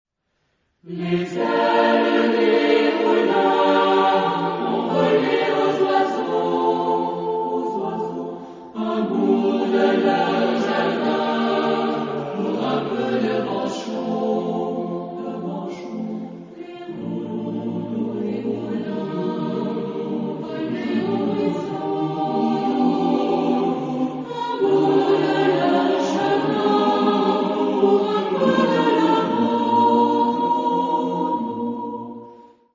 Genre-Style-Forme : Profane ; Danse
Caractère de la pièce : dansant
Type de choeur : SATB  (4 voix mixtes )
Tonalité : si bémol majeur
Consultable sous : 20ème Profane Acappella